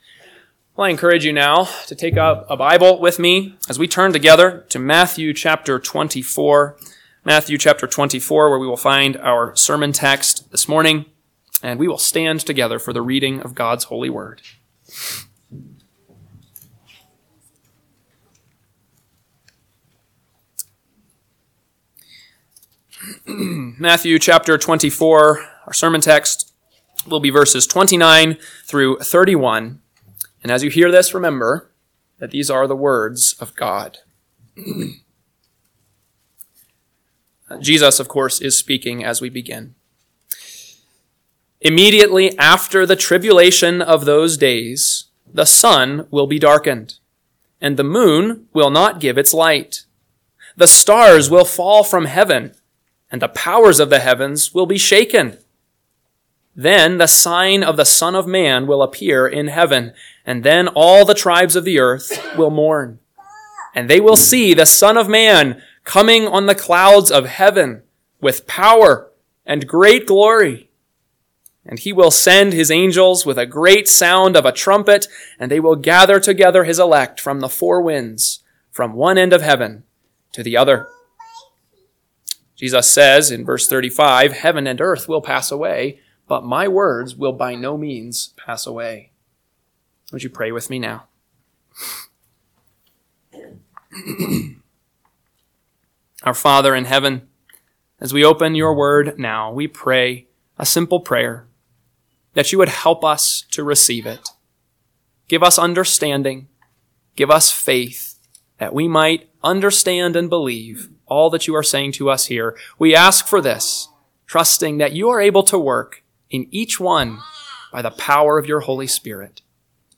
AM Sermon – 12/22/2024 – Matthew 24:29-31 – Northwoods Sermons